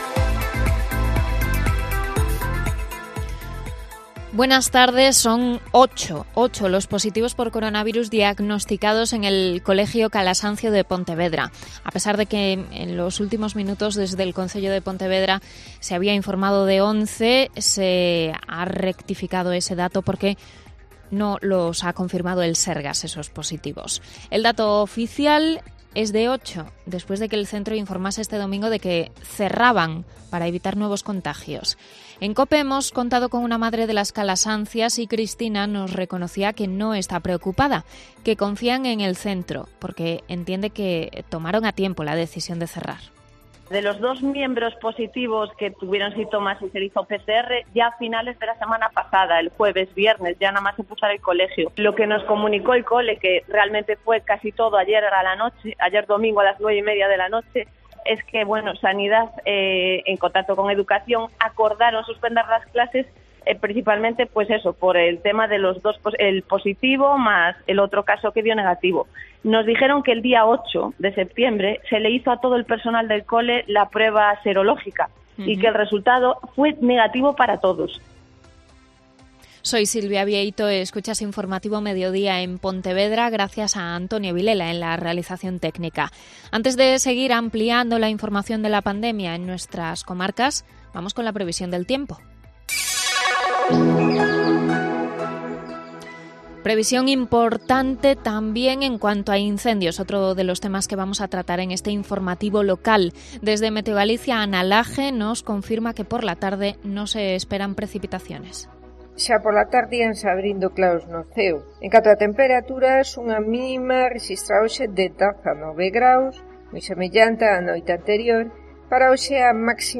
Mediodía COPE Pontevedra (Informativo 14:20)